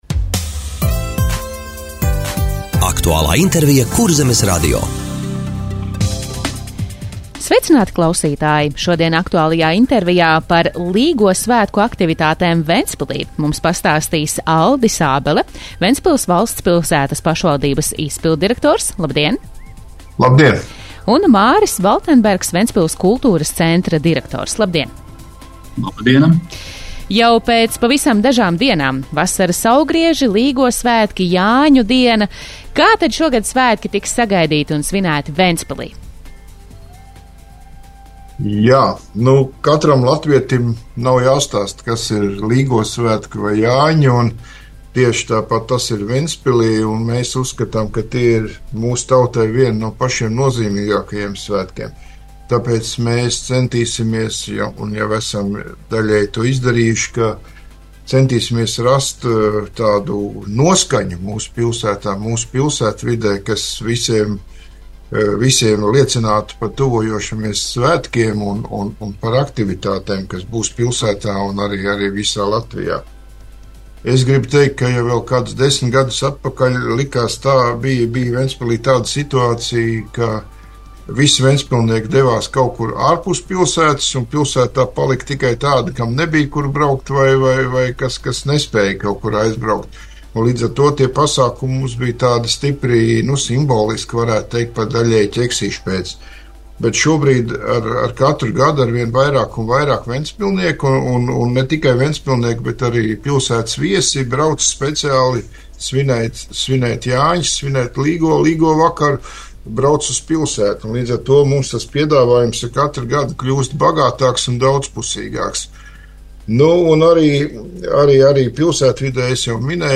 Radio saruna Līgo svētku aktivitātes Ventspilī